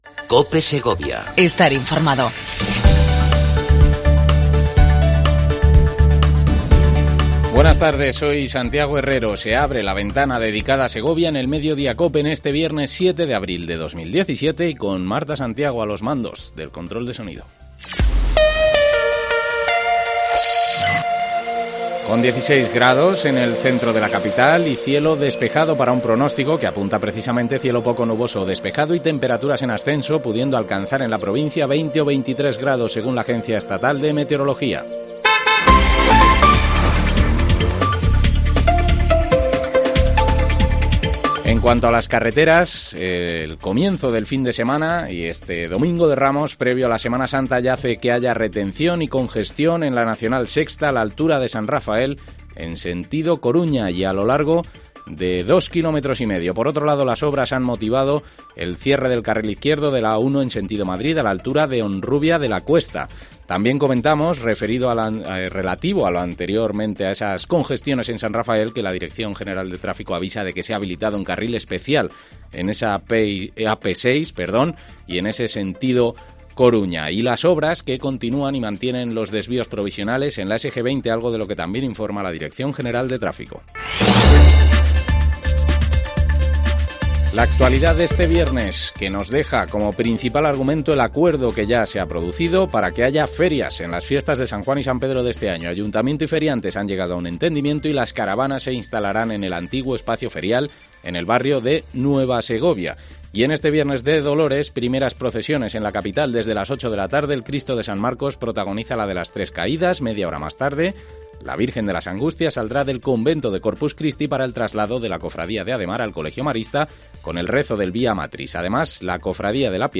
Entevista